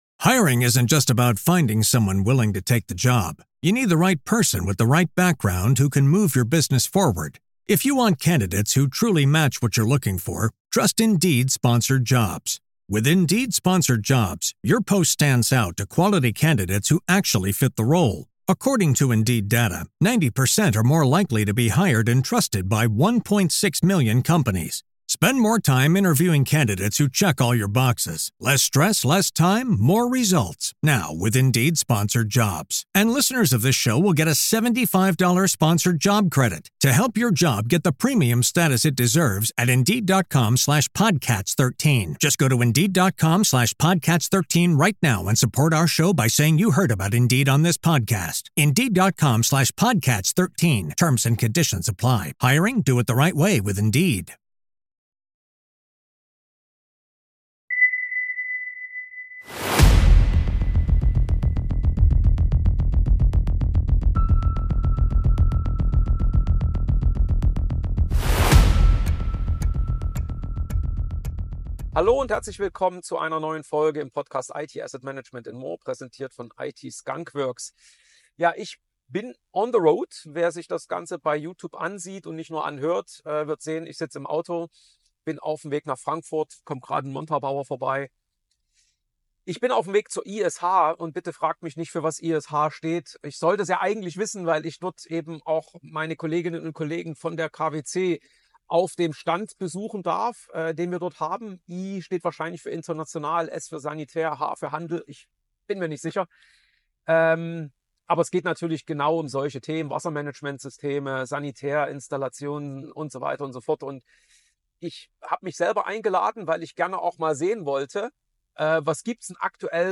Beschreibung vor 1 Jahr Warum ein IT-Demand-Management unverzichtbar ist! In dieser Episode nehme ich euch mit auf eine Reise – buchstäblich, denn ich bin on the road!